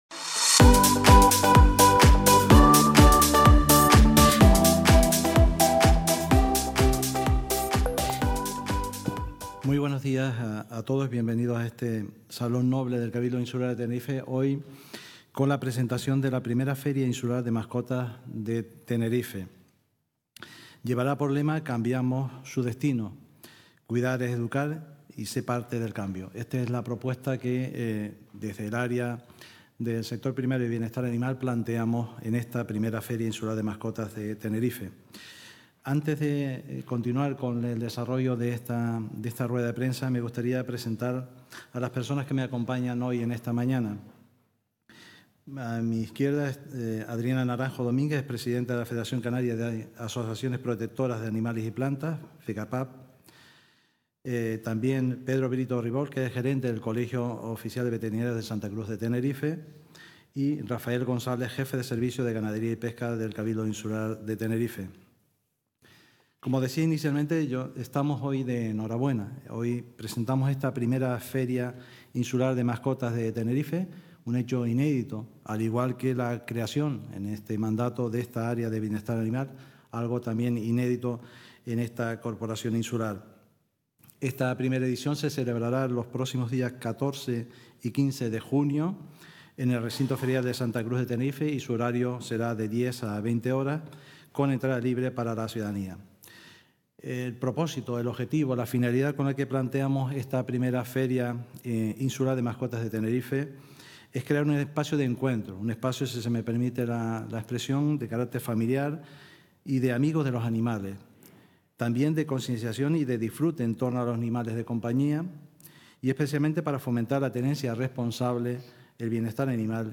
Presentación